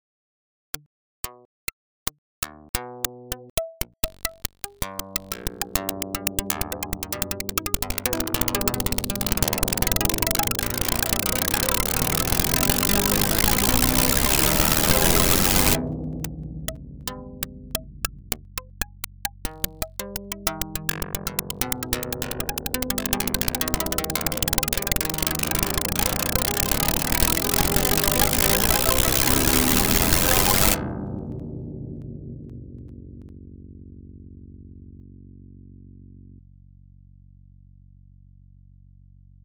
The snippet below implements Karplus strong w/ a delay line of 1024 samples - every voice gets allocated and compiled on the RT thread w/o any dropouts on 30% CPU usage.